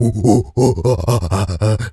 Animal_Impersonations
gorilla_chatter_04.wav